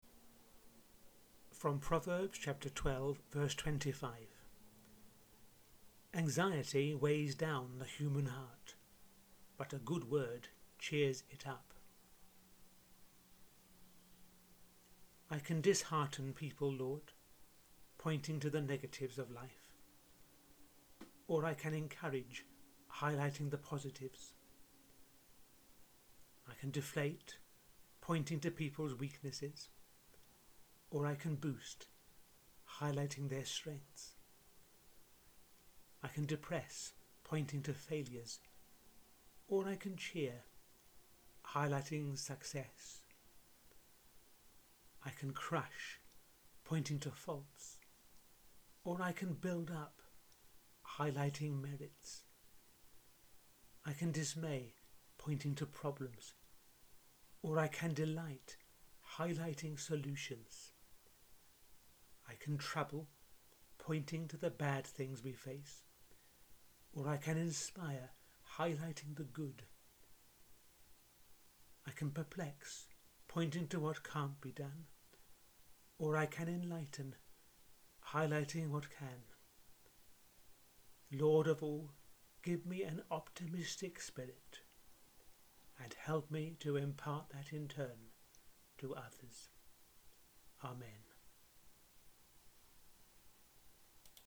The tenth prayer in the audio series I’m running over these few weeks: